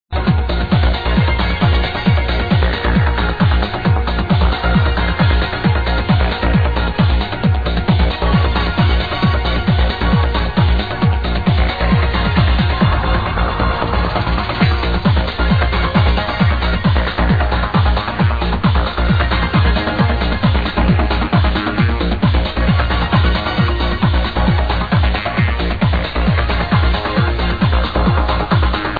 Here is a bangin tune with samples that remind me very much Transa's work.
Nice banging track.
There are some similar elements in the beat, but besides that, the main melody is much different from Astro Dawn's, and i think that theres not any other version of this song than the original mix...